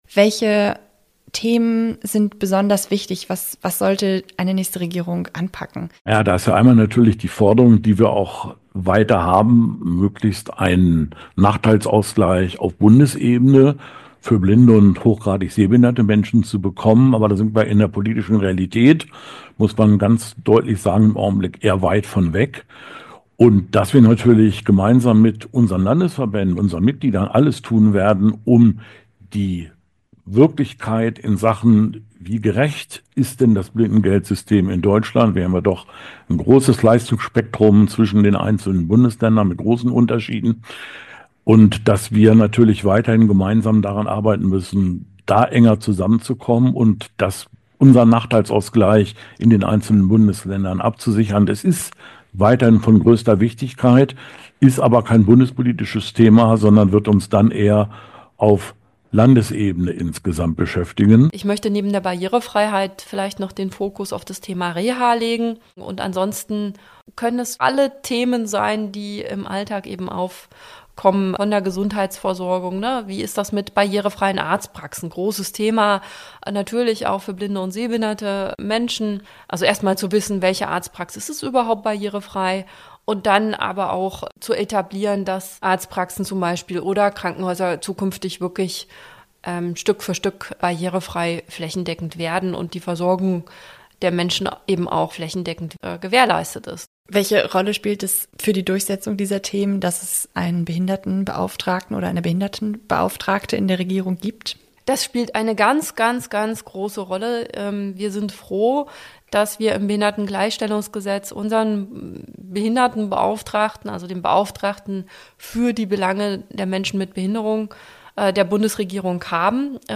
Interview 24.01.2025: Bundestagswahl aus DBSV-Sicht